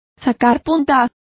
Complete with pronunciation of the translation of sharpening.